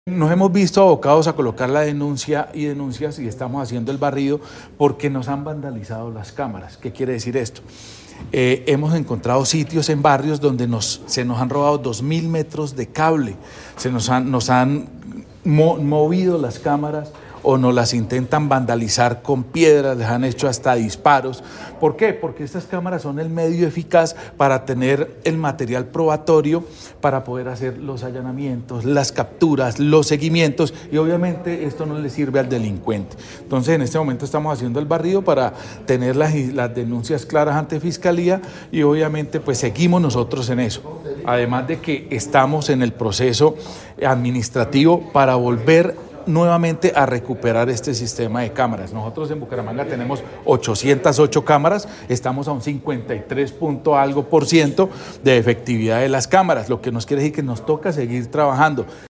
Gildardo Rayo, Secretario del Interior de Bucaramanga